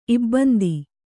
♪ ibbandi